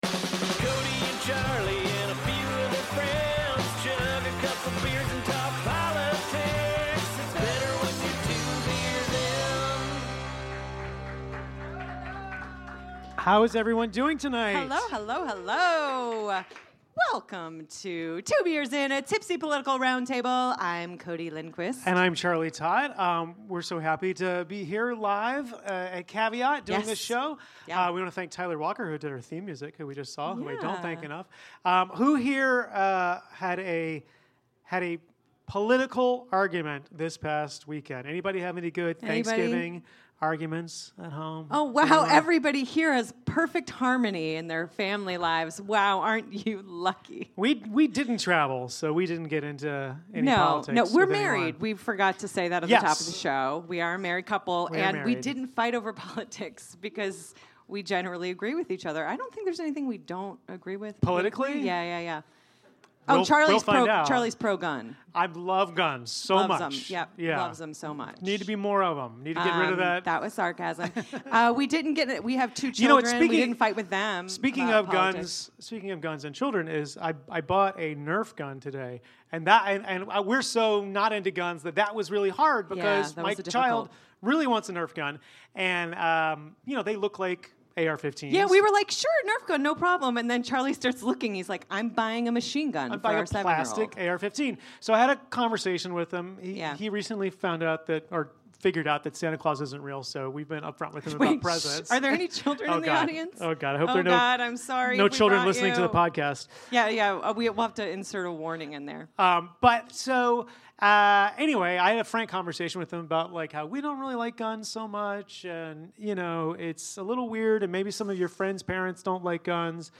Recorded live at Caveat NYC